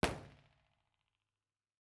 IR_EigenmikePL001L1_processed.wav